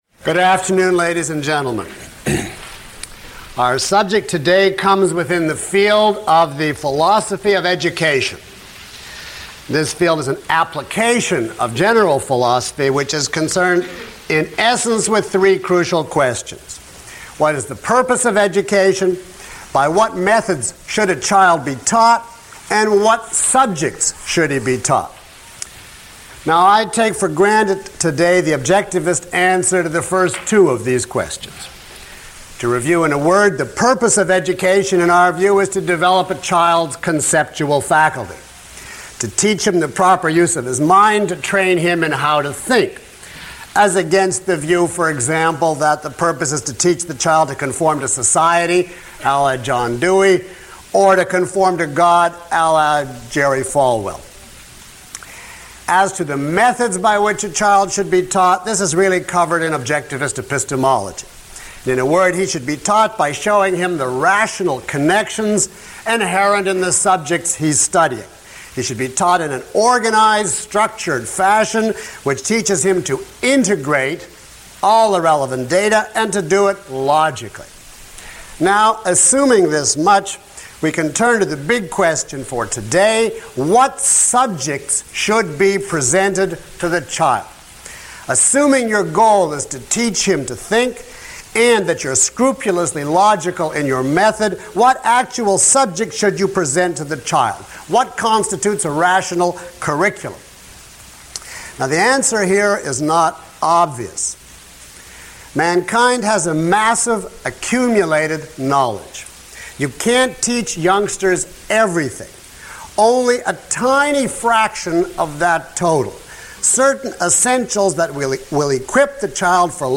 Delivered at the Jefferson School One-Day Regional Seminar in NYC, on October 11, 1986.
Lecture (MP3) Questions about this audio?